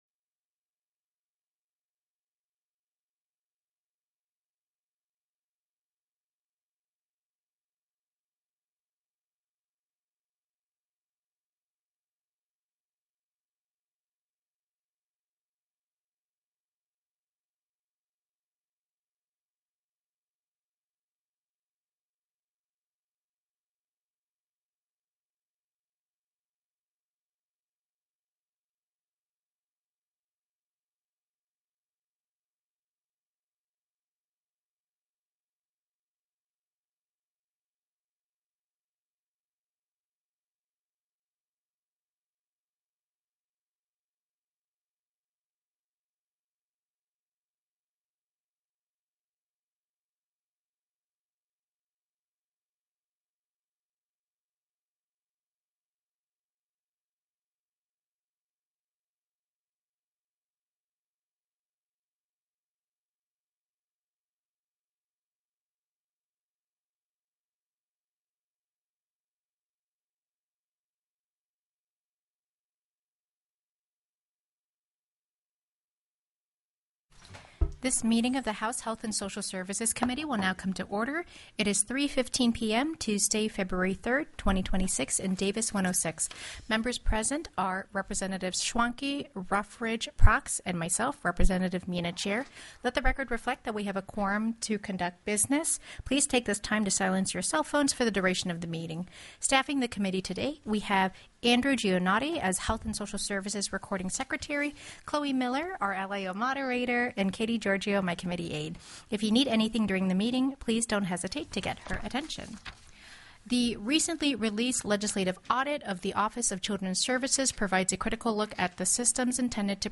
The audio recordings are captured by our records offices as the official record of the meeting and will have more accurate timestamps.
Presentation: Office of Children's Services TELECONFERENCED Legislative Audit by Commissioner Tracy Dompeling, Department of Family & Community Services and Kim Guay, Director, Office of Children's Services + SB 83 TELEHEALTH: COVERAGE, REIMBURSEMENT RATES TELECONFERENCED Heard & Held